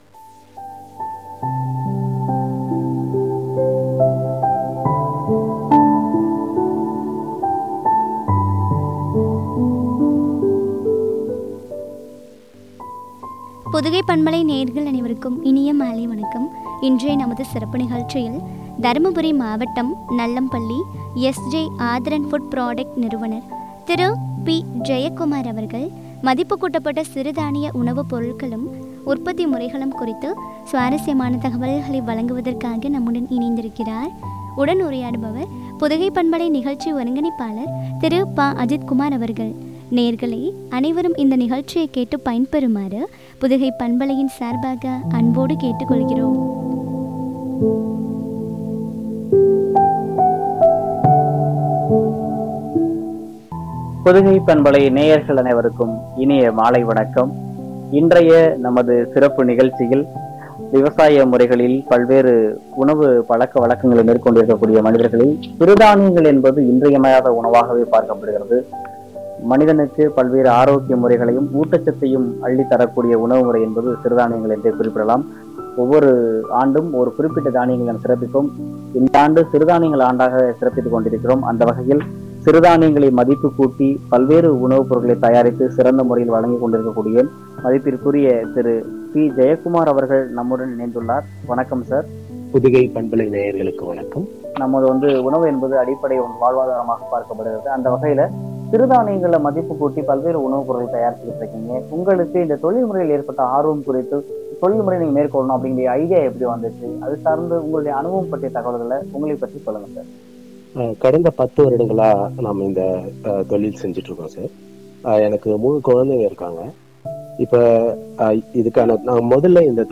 உற்பத்தி முறைகளும் ” குறித்து வழங்கிய உரையாடல்